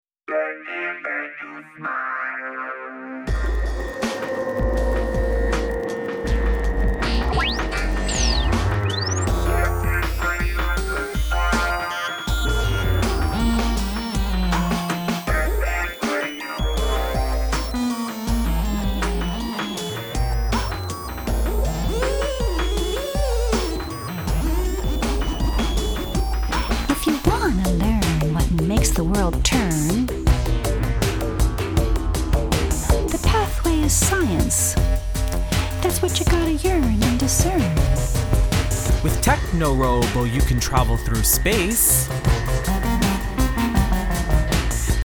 new concept in children's music